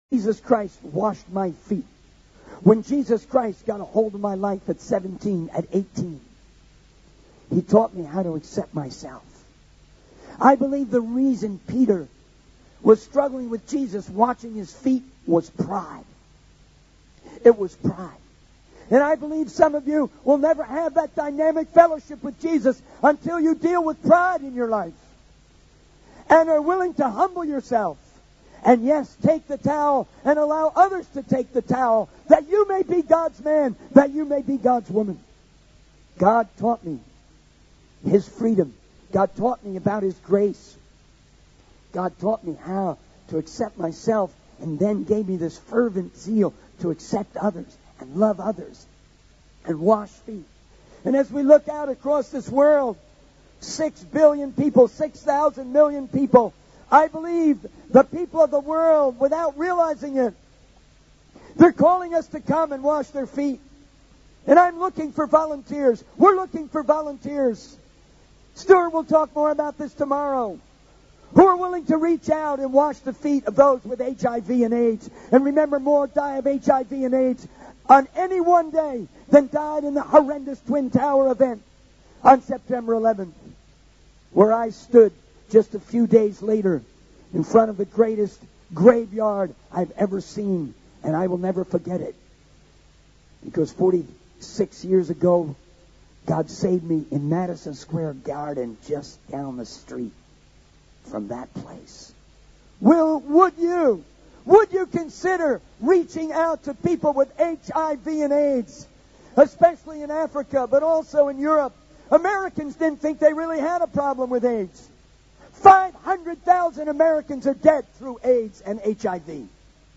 The sermon emphasizes the importance of humility and serving others, and how God can use anyone, regardless of their past failures, to make a difference in the world.
Sermon Outline